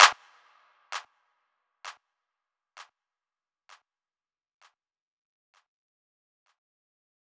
DMV3_Clap 21.wav